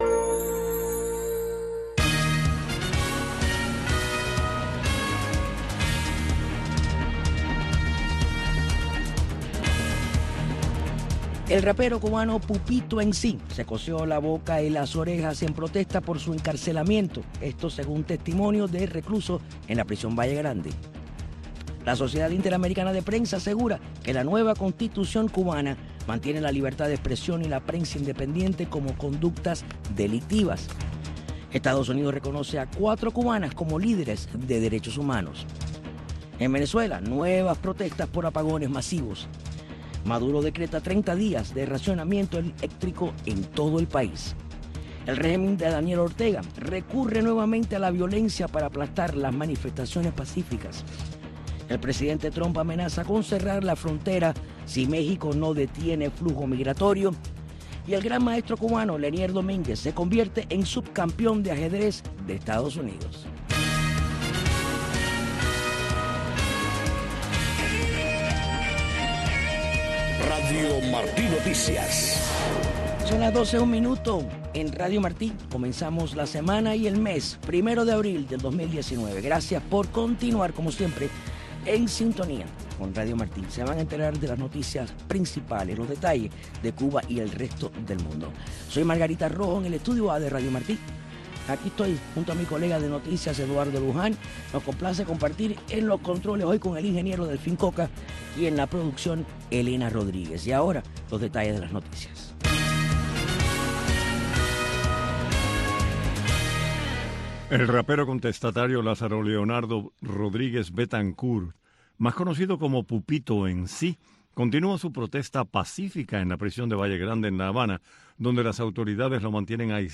Noticiero de Radio Martí 12:00 PM